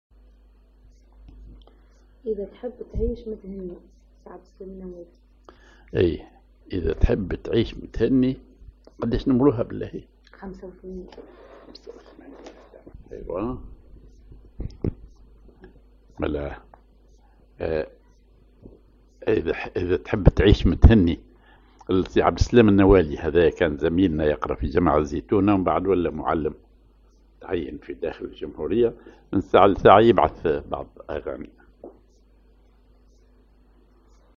Maqam ar حسين
Rhythm ar دويك
genre أغنية